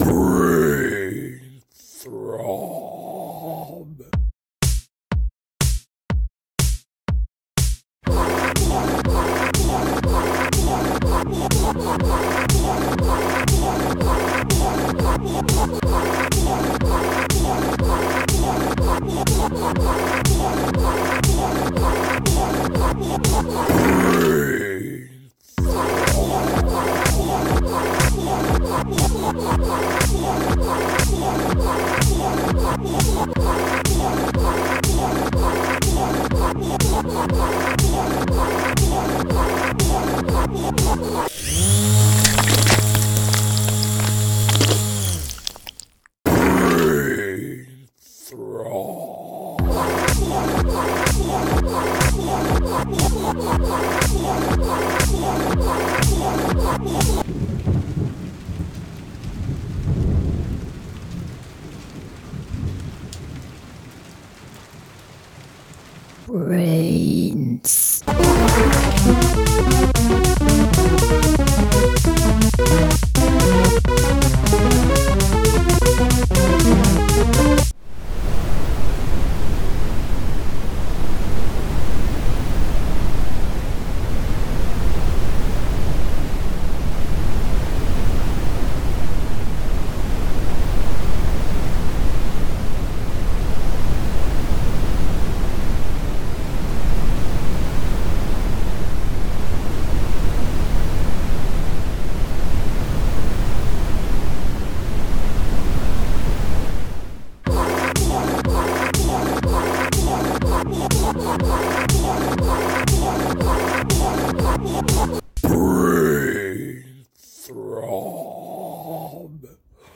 BPM1-999
Audio QualityMusic Cut
Remixed by me